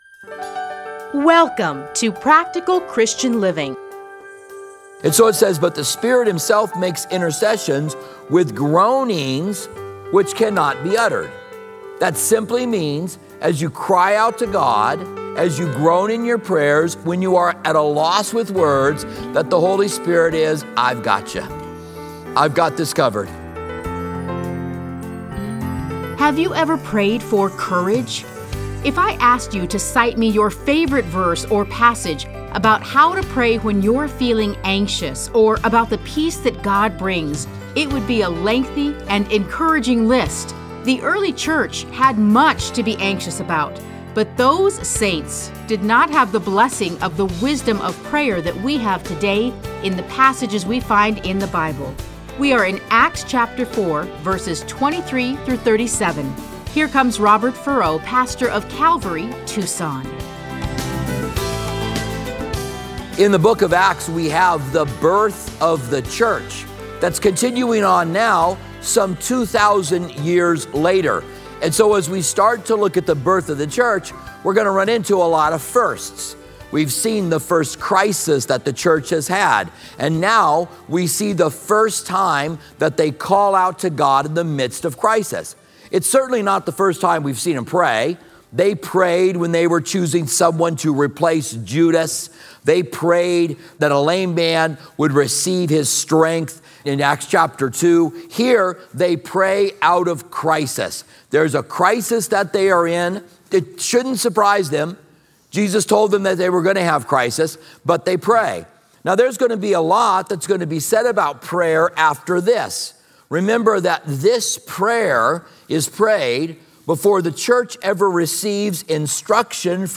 Listen to a teaching from Acts 4:23-37.